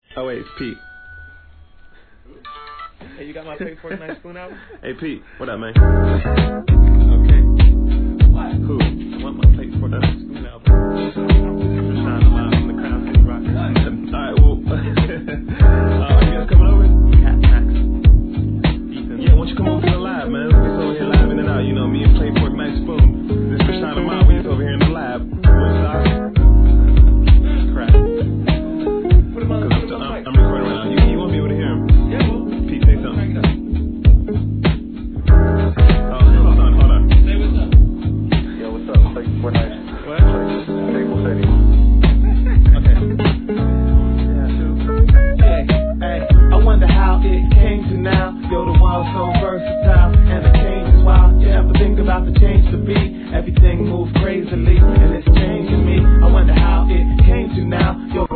1. HIP HOP/R&B
JAZZ FUNK HIP HOPのブレイク物!!